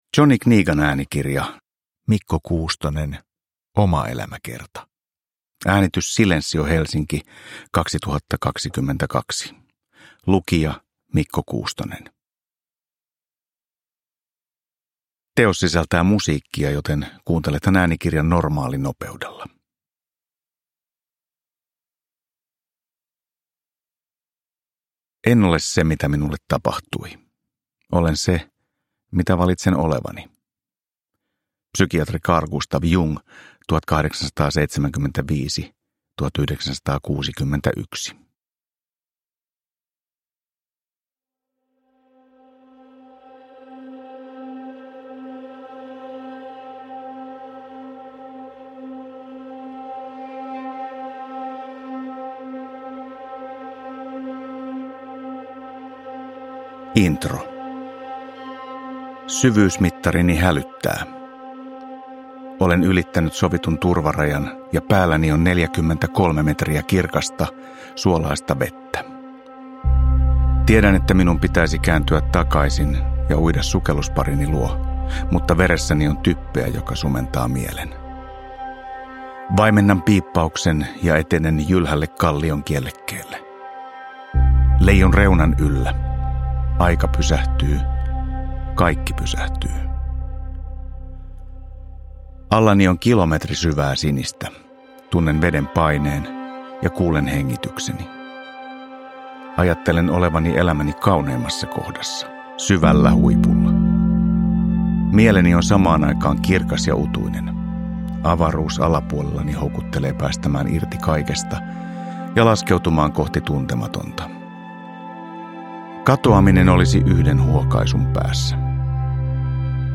Omaelämäkerta – Ljudbok – Laddas ner
Ainutlaatuinen yhdistelmä sanoja ja säveliä.
Omaelämäkerta yhdistää ensimmäistä kertaa kirjan, äänimaiseman ja albumillisen uusia lauluja.
Uppläsare: Mikko Kuustonen